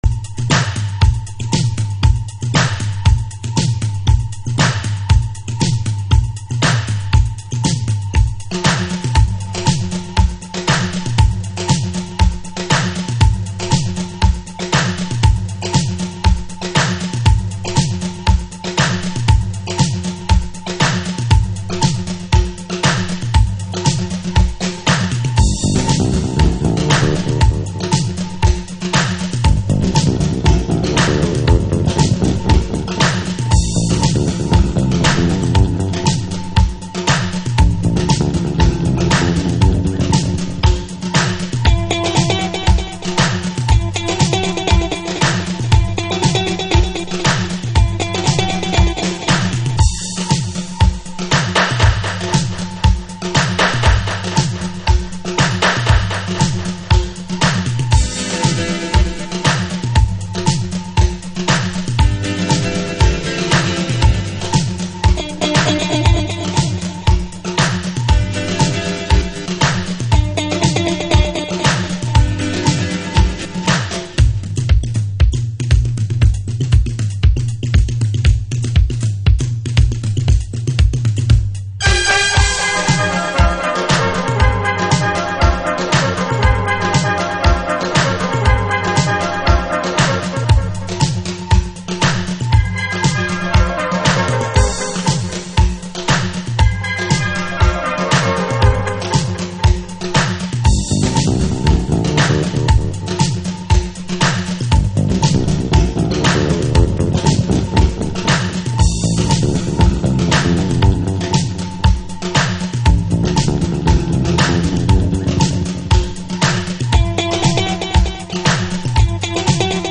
Alt Disco / Boogie
脈々と続くリエディットという様式。